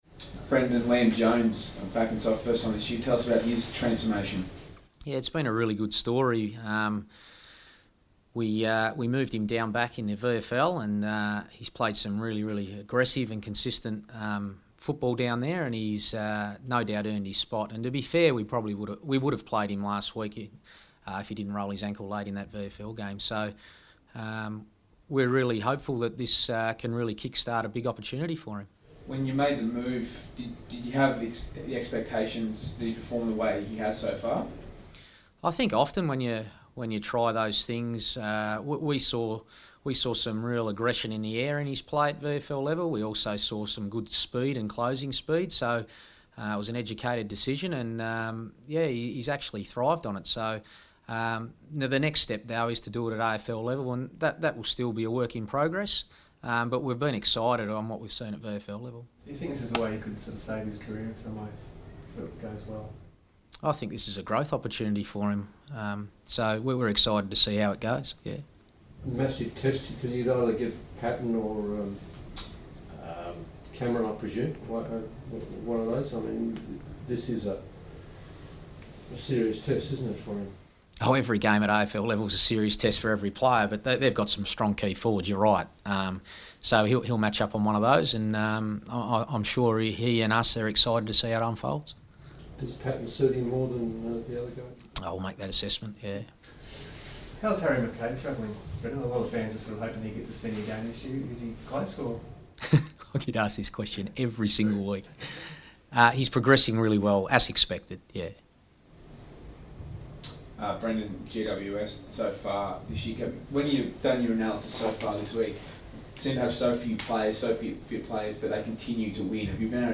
Brendon Bolton press conference - June 10
Carlton coach Brendon Bolton fronts the media ahead of the Blues' clash with GWS.